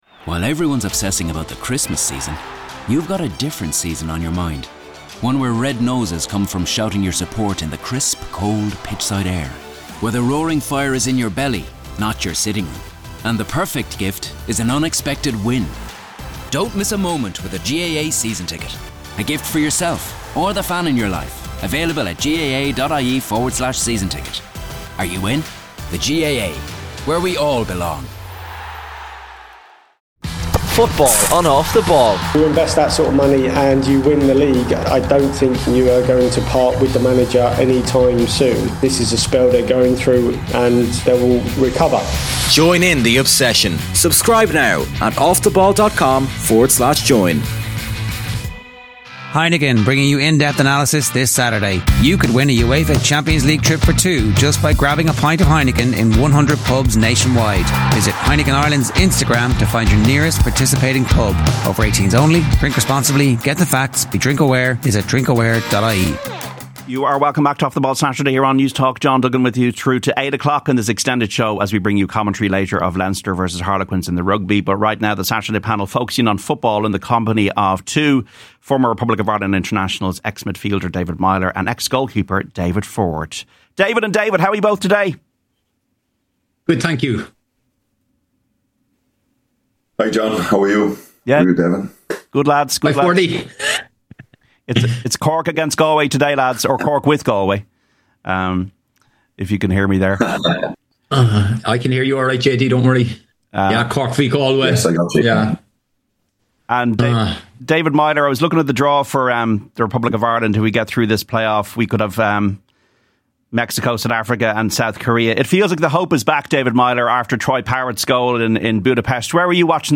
OTB's Saturday Panel